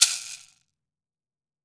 WOOD SHAKER5.WAV